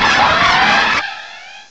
cry_not_empoleon.aif